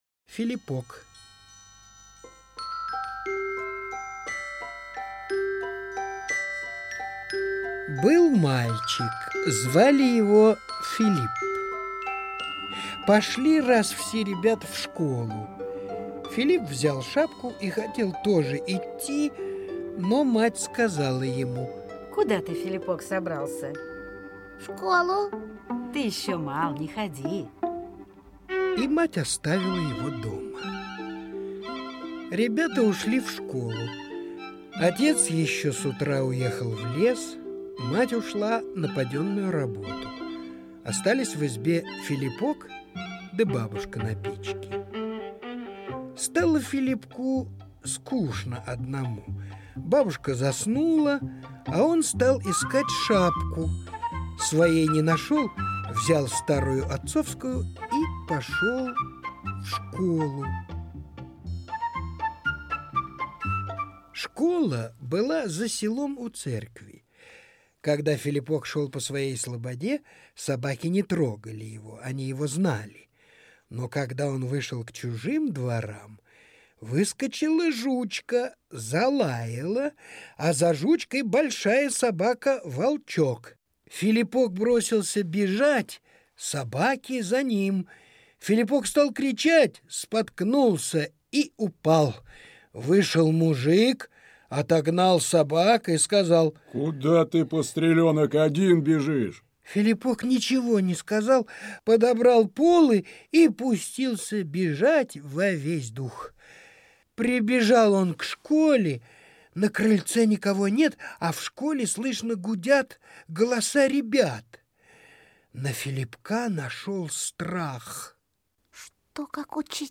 Слушайте "Филипок" - аудио рассказ Толстого Л.Н. Рассказ про маленького мальчика, который очень хотел ходить в школу, но был еще мал.